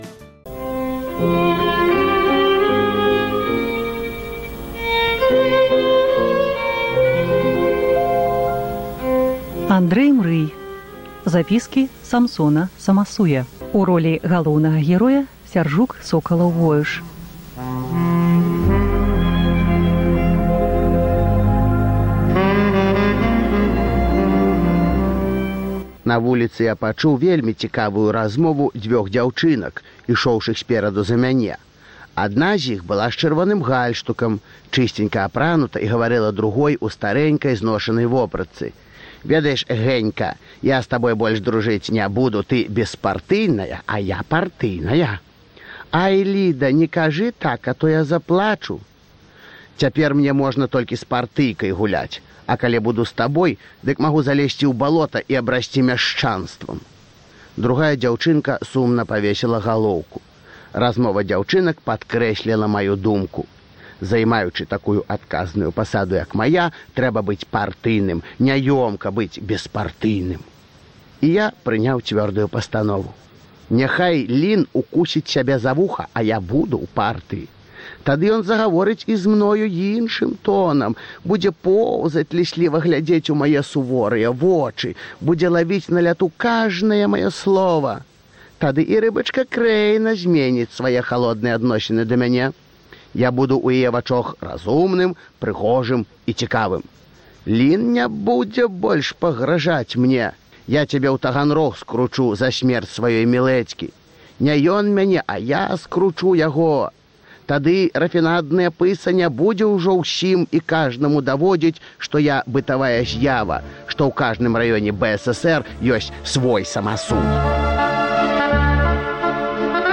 Чытае Сяржук Сокалаў-Воюш.